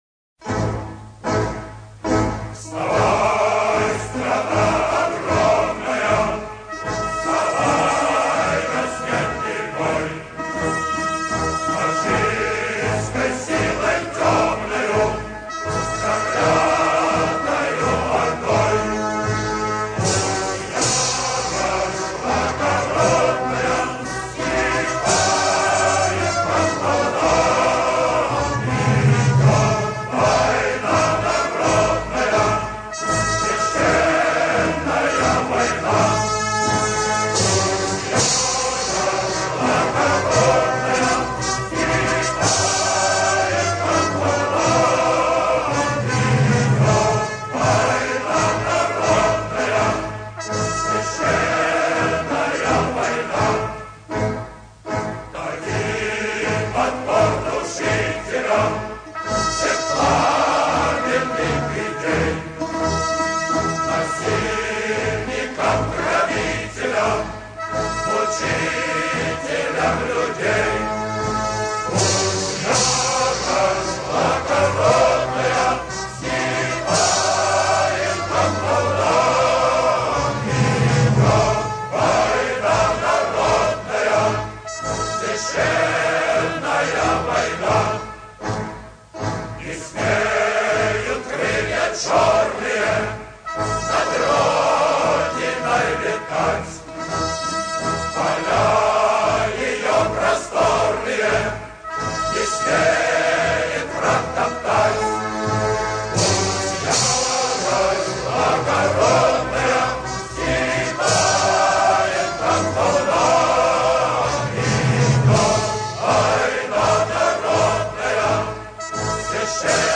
аудиозапись песни